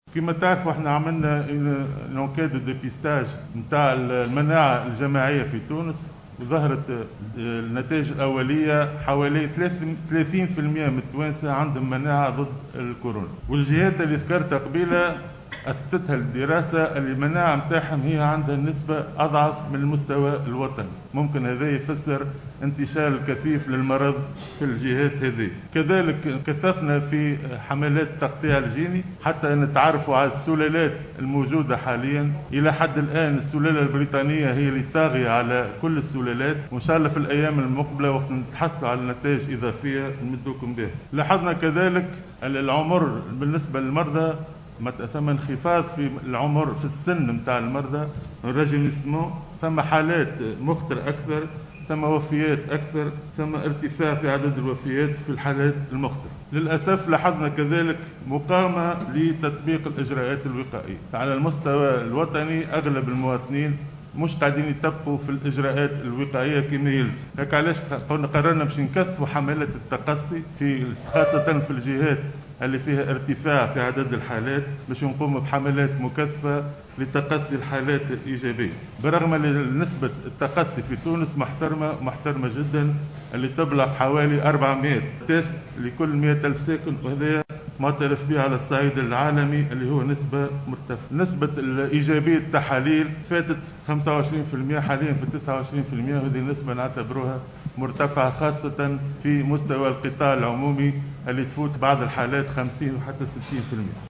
وقال الوزير خلال ندوة عقدها اليوم بمقر الوزارة، إنّه تمّ تكثيف حملات التقطيع الجيني لمعرفة أنواع السلالات وأثبتت التحاليل أنّ السلالة البريطانية هي الطاغية في تونس.